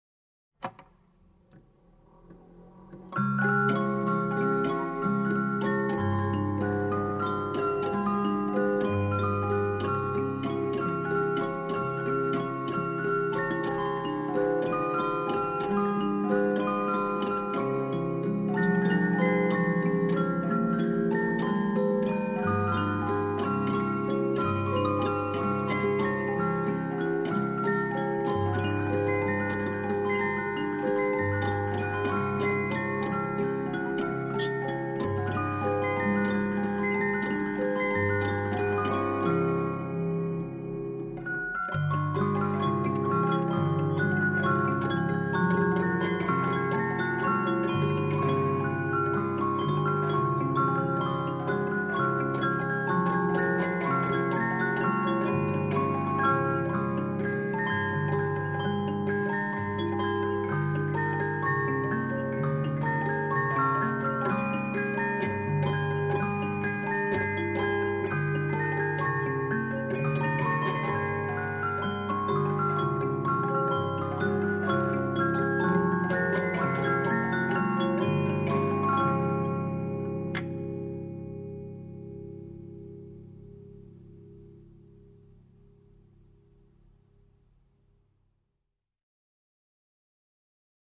Boîte à musique – Music Box: I. Parigi o cara (Traviata) – II. Le Canard à 3 Becs